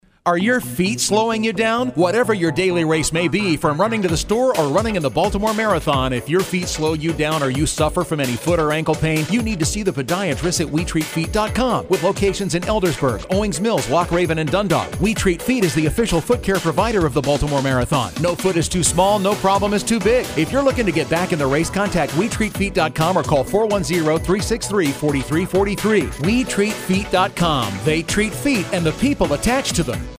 We Treat Feet Radio Commercial 2 Berman’ s Jewelers radio commercial